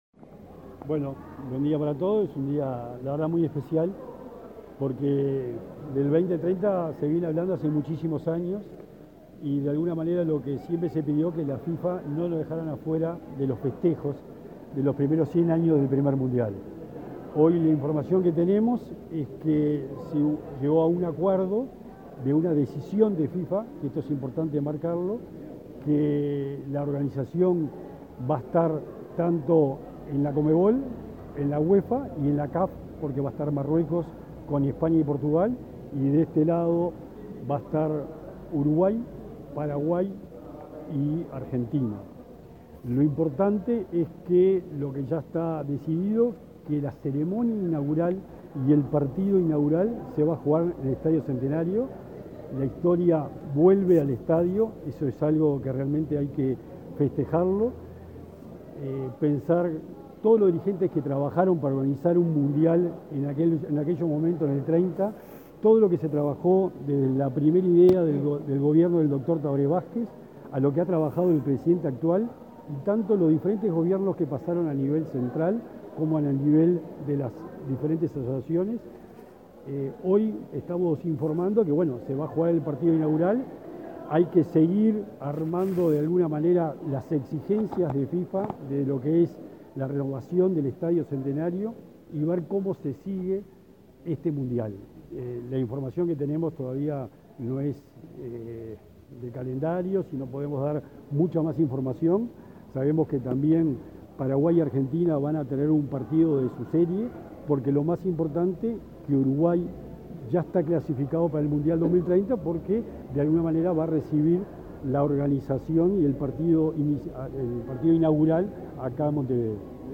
Declaraciones del secretario nacional del Deporte, Sebastián Bauzá
En ese contexto, dialogó con la prensa acerca de la Copa Mundial de Fútbol 2030.